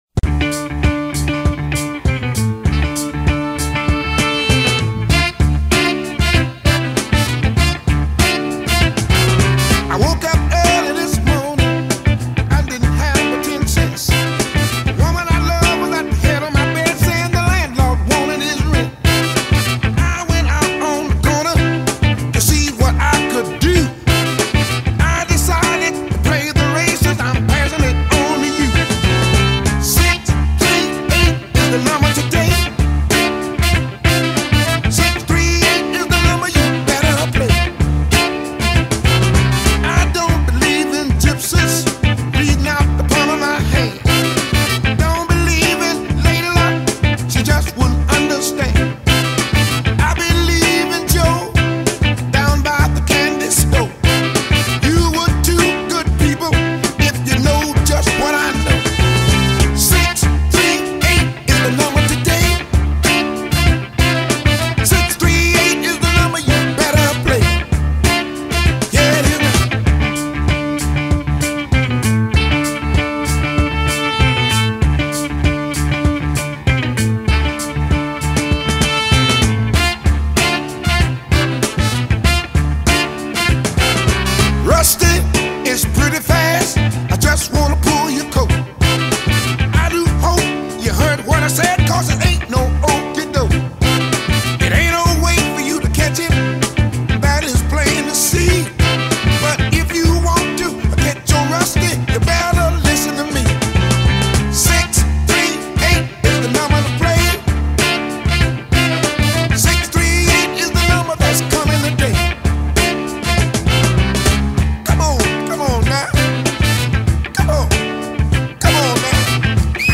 TEMPO: 95
plutôt funky, bonne section cuivre en arrière plan.